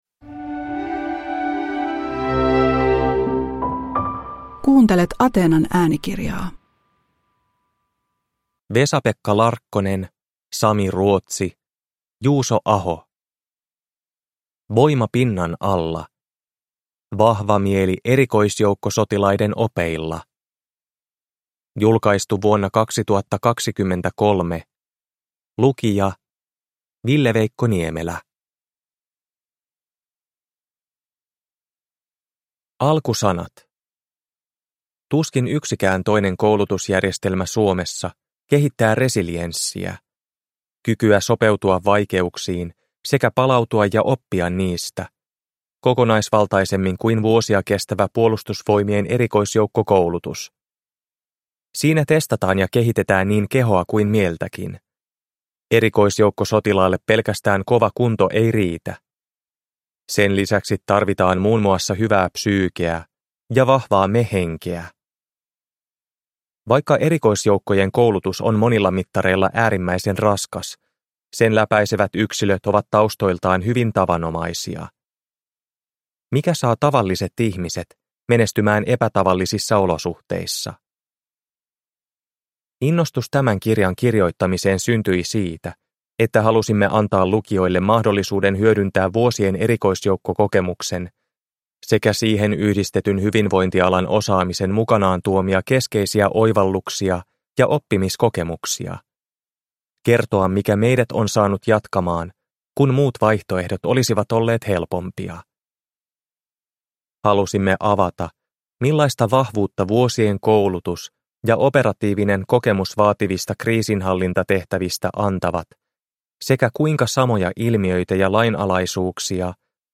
Voima pinnan alla – Ljudbok